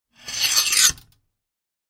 Металлический скрежет выдвижной штанги телескопа